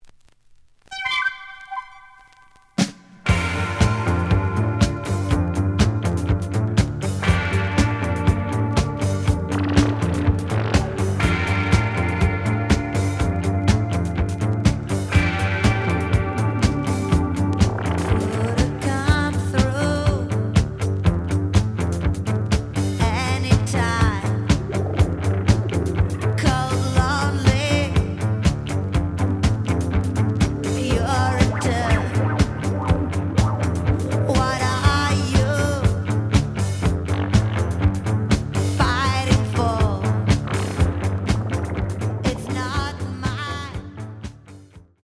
The audio sample is recorded from the actual item.
●Genre: Rock / Pop
Edge warp. But doesn't affect playing. Plays good.)